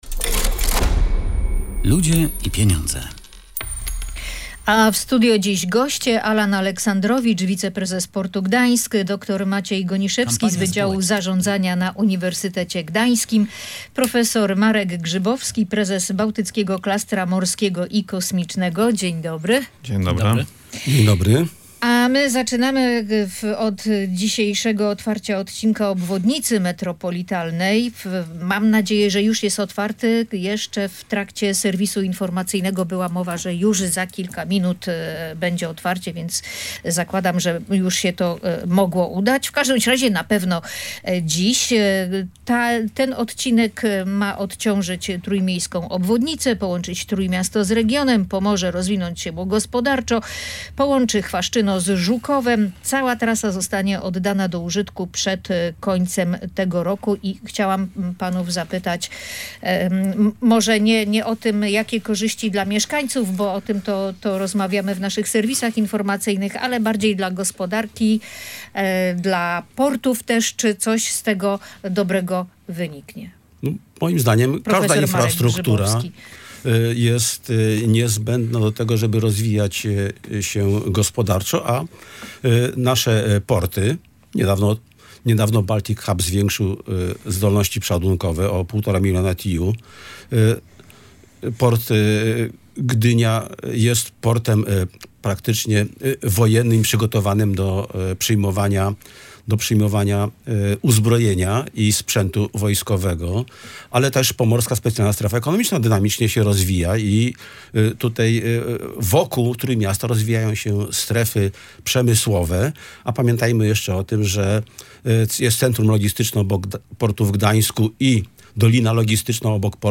Otwarcie odcinka Obwodnicy Metropolitalnej Trójmiasta przyspieszy rozwój gospodarczy regionu i wpłynie na procesy osiedleńcze - wskazywali goście audycji "Ludzie i